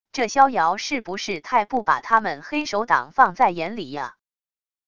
这萧摇是不是太不把他们黑手党放在眼里啊wav音频生成系统WAV Audio Player